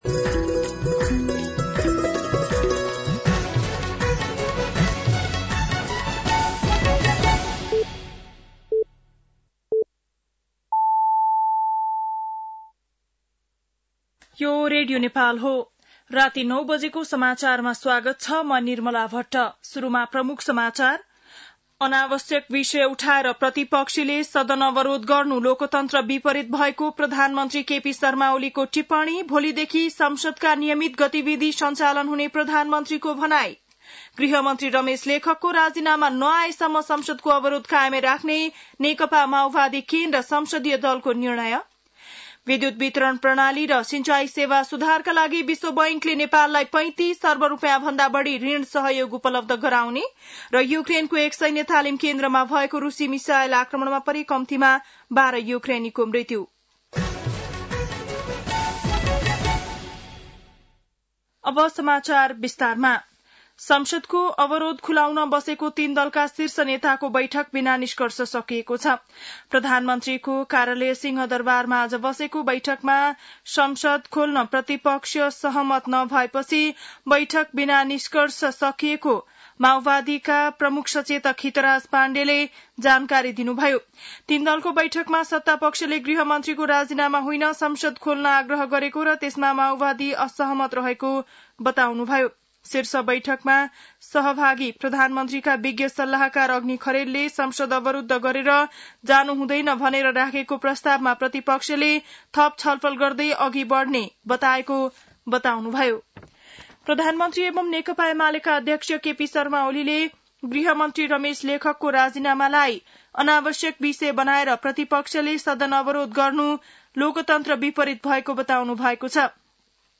बेलुकी ९ बजेको नेपाली समाचार : १९ जेठ , २०८२
9-PM-Nepali-NEWS-1-1.mp3